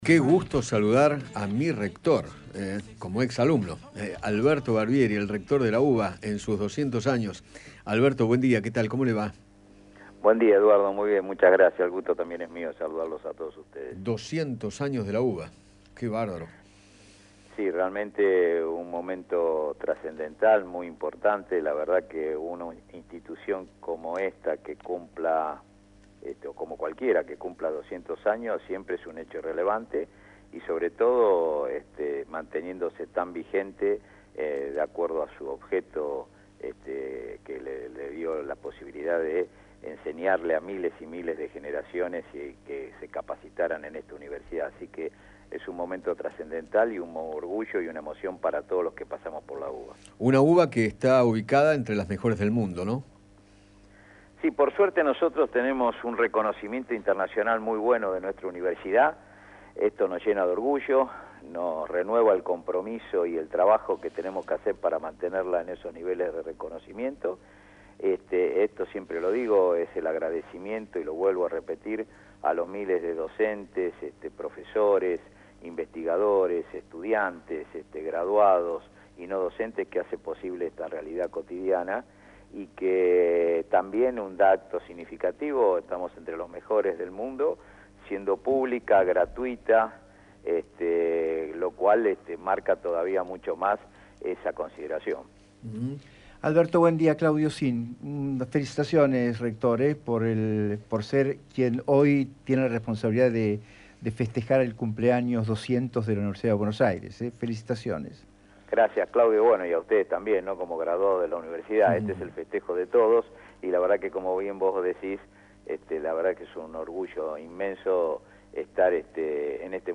Alberto Barbieri, rector de la Universidad de Buenos Aires, dialogó con Eduardo Feinmann acerca del bicentenario de dicha institución educativa.